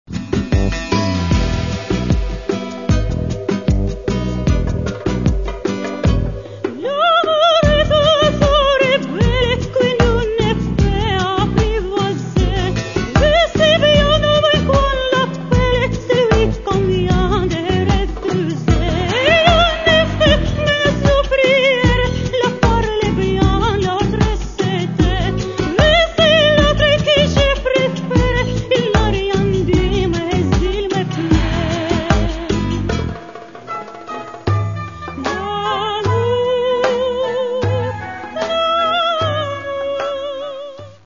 Catalogue -> Classical -> Opera and Vocal